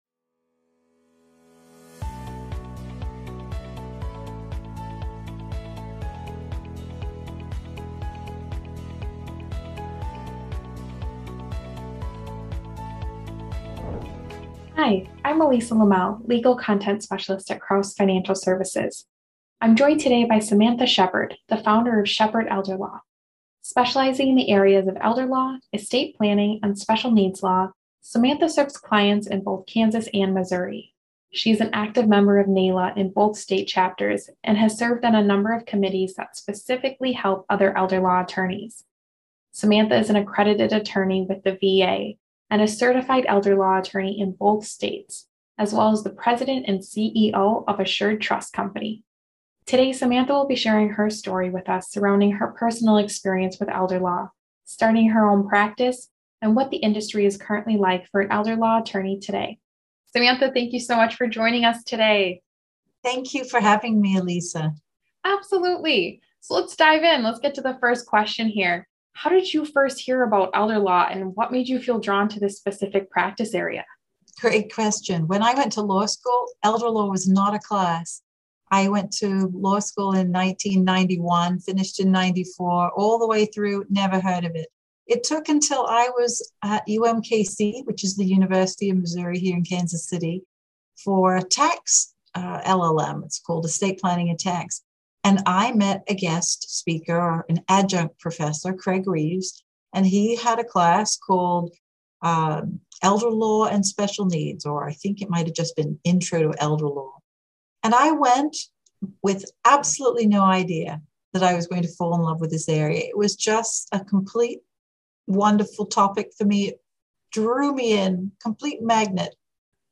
Whether you are thinking about an elder law career or have practiced in this area for years, this interview contains helpful considerations.